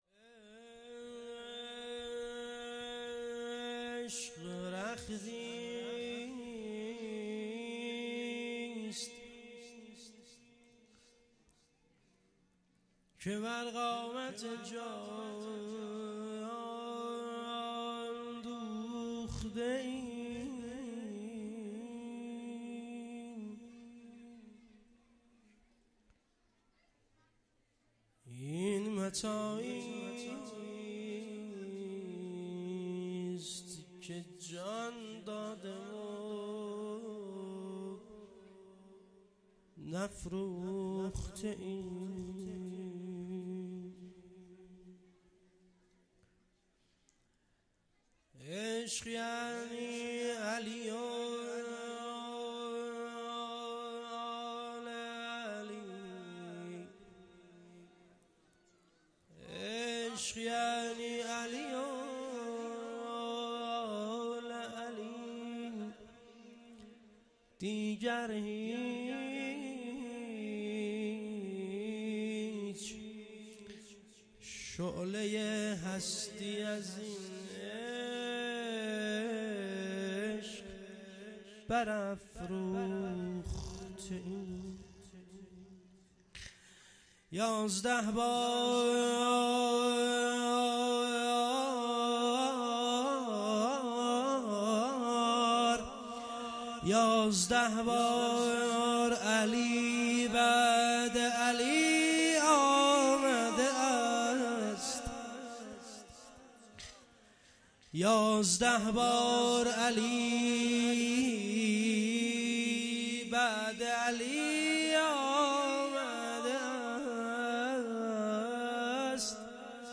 جشن ولادت امام زمان نیمه شعبان 1446